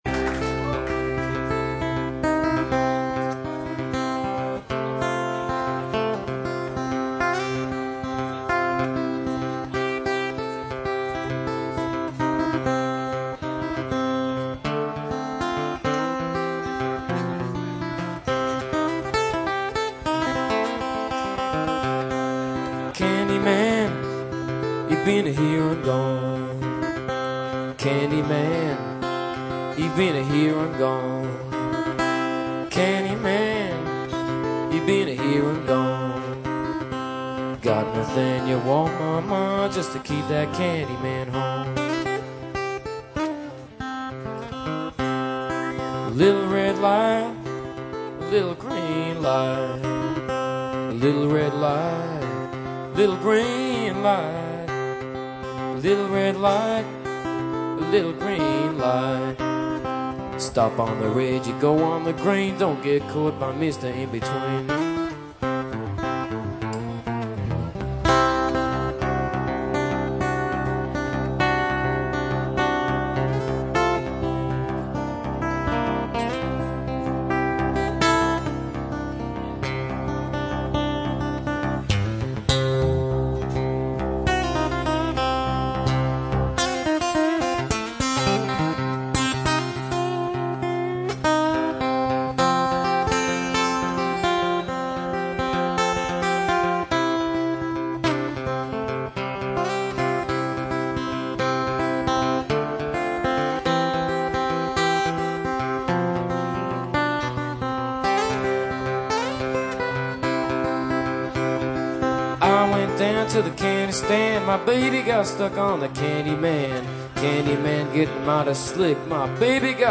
acoustic guitar, lap steel, dobro, harmonica, vocals
drums
fiddle, guitar
bass